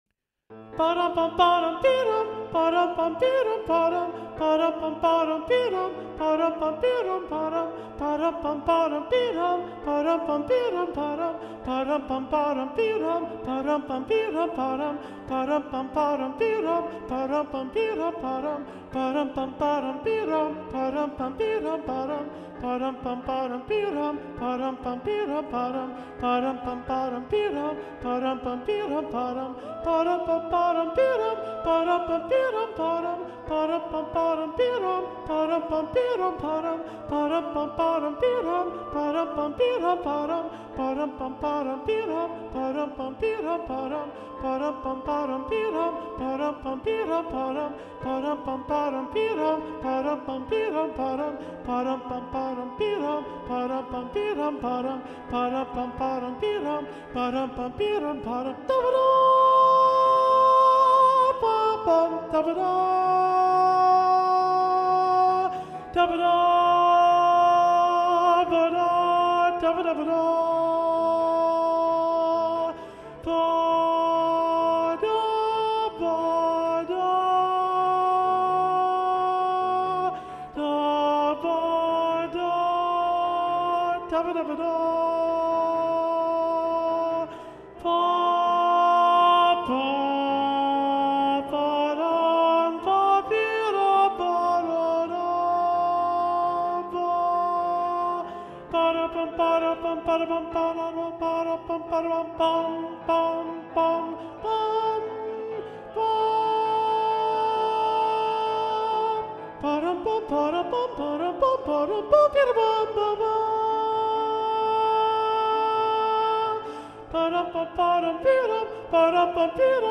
- Œuvre pour chœur à 7 voix mixtes (SSAATTB) + piano
SATB Alto 1 (chanté)